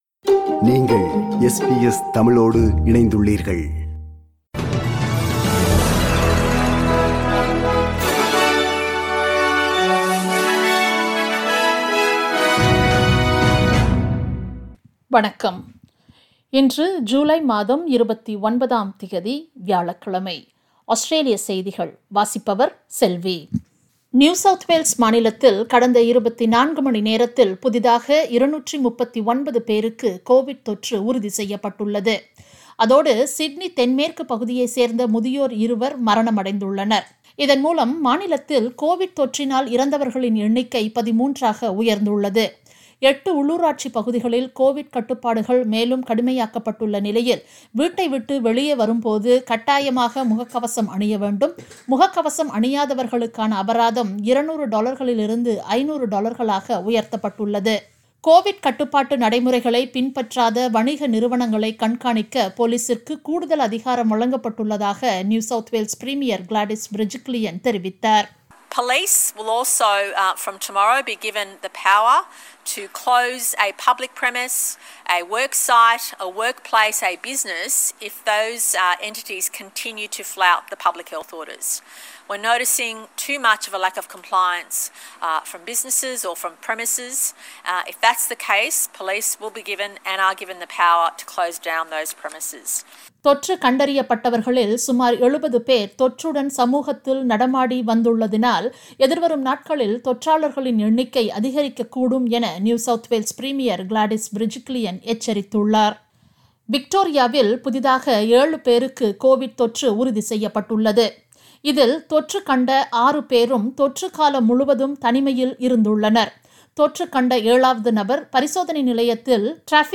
Australian news bulletin for Thursday 29 July 2021.